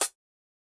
Perc (11).wav